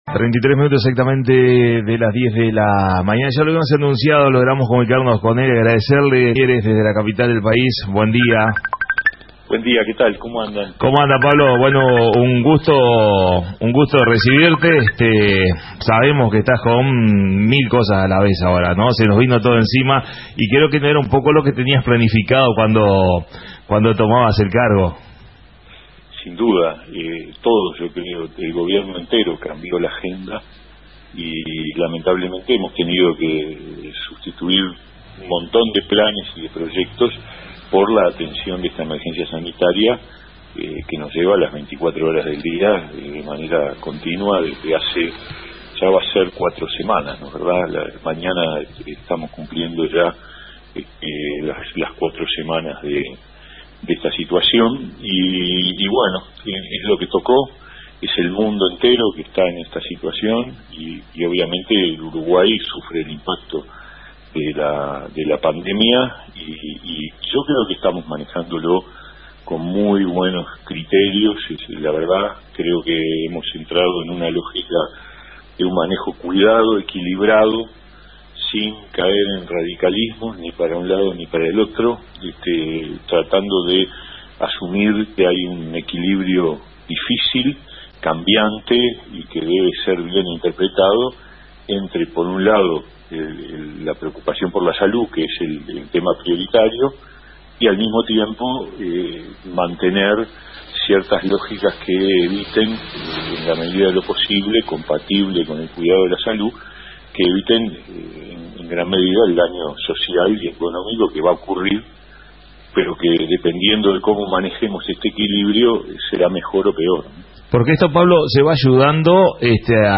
Entrevista al Ministro de Trabajo y Seguridad Social Dr Pablo Mieres.